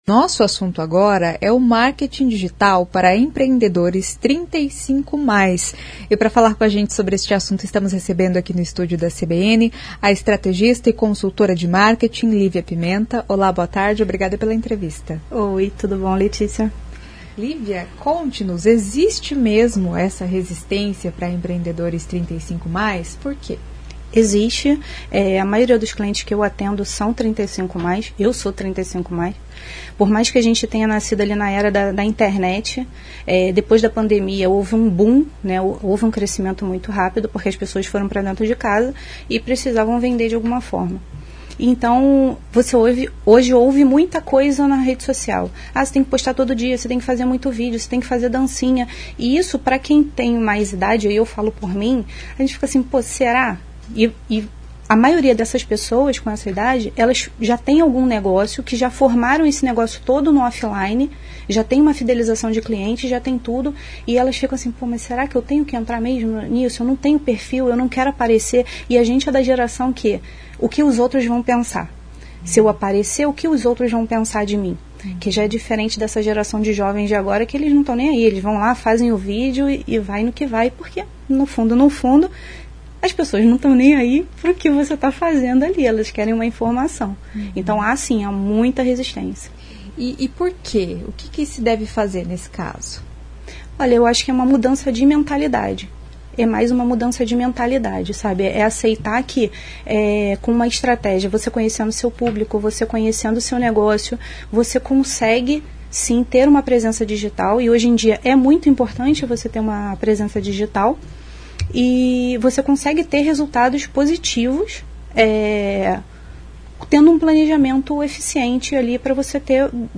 A estrategista e consultora de marketing fala sobre estratégia de marketing.